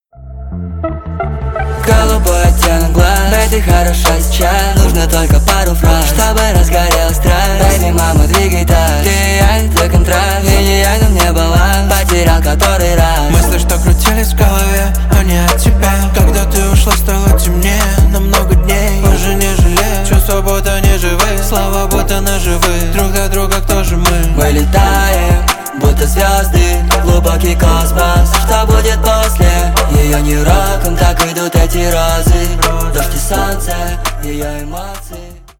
Рингтоны на звонок
Нарезка припева на вызов